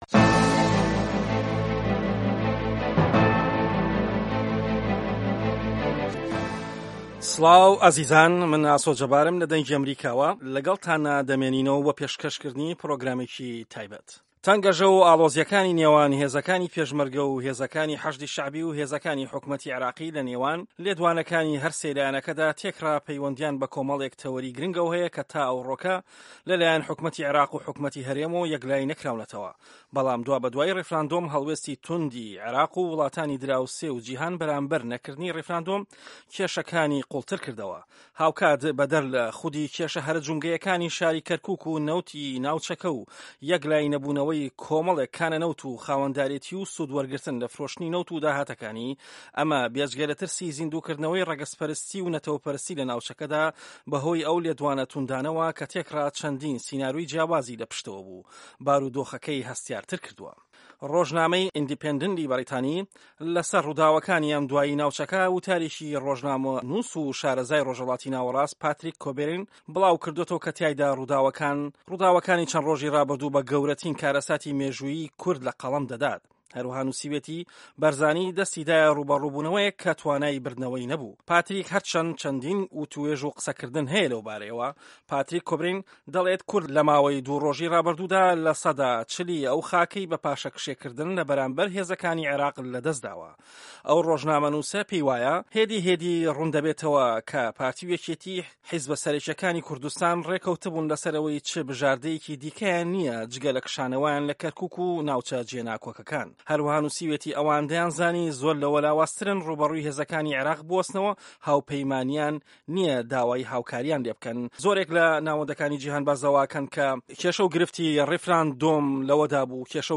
دەقی مێزگردەکە